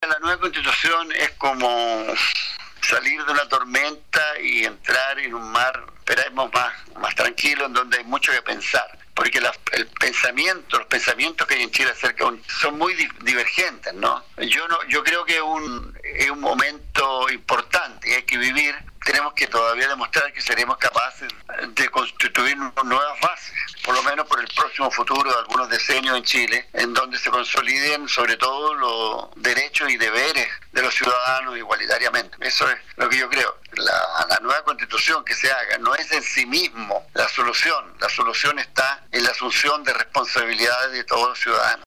El charanguista atiende el teléfono a Radio UC desde Chiloé, donde vive hace un par de años junto a su familia.